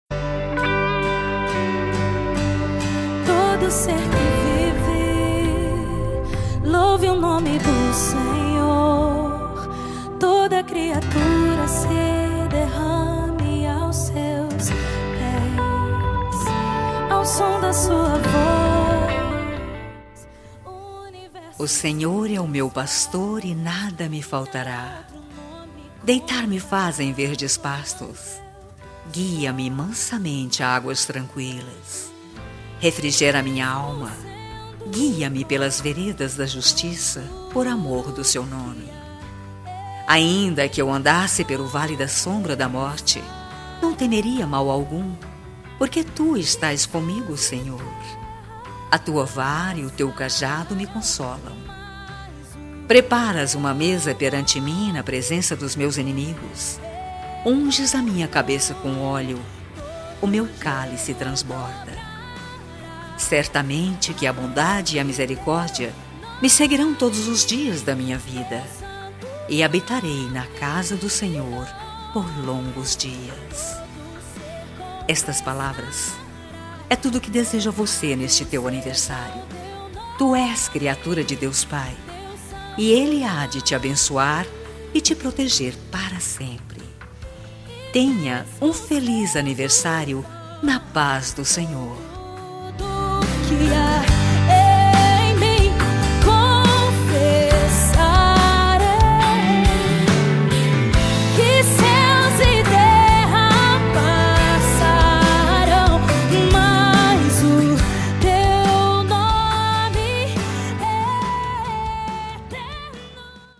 Voz Feminina
Código: 040405 – Música: Teu Santo Nome – Artista: Gabriela Rocha